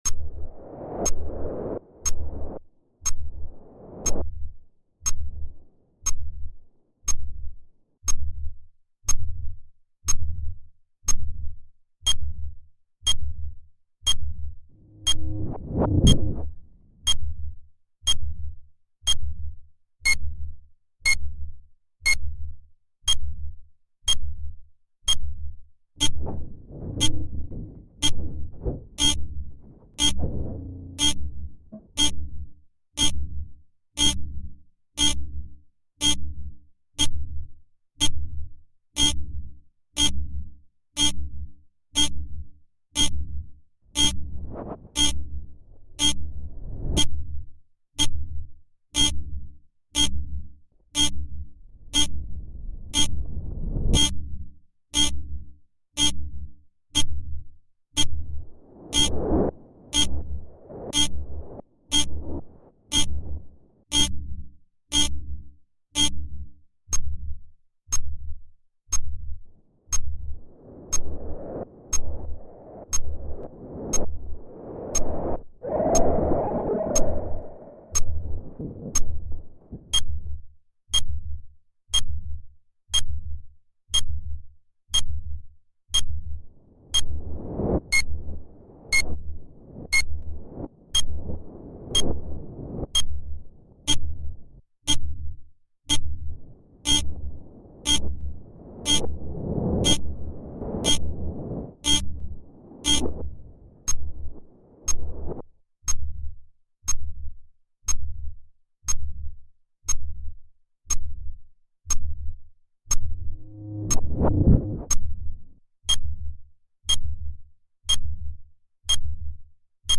We sent sound into the tree and, with it, explored her hidden architecture. But what did we hear exactly? Traffic passing by above ground?
Below is the sound recording, on your left ear, the INPUT sound that we use to trace and track, on your right ear, the OUTPUT sounds of the rootsystem, sapstream, car-passing and unnameable soundbits.